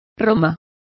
Complete with pronunciation of the translation of Rome.